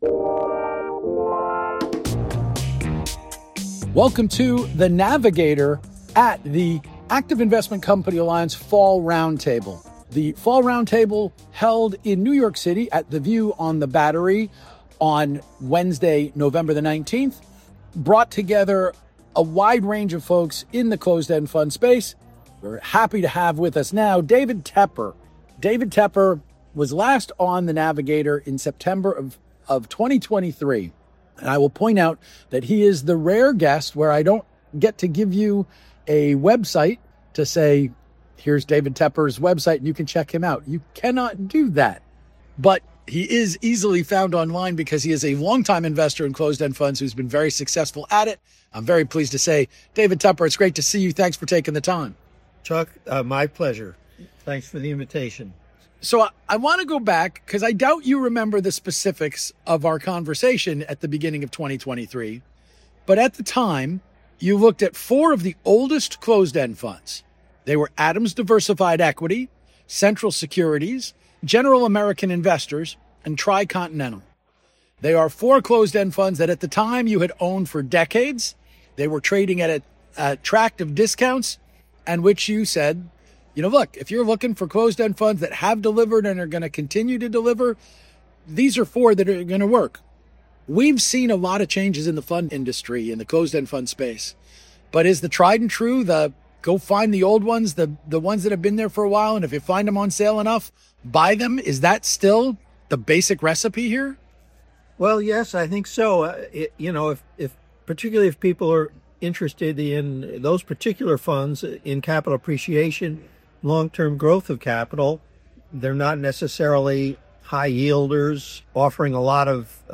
In an interview recorded at the Active Investment Company Alliance Fall Round Table in New York City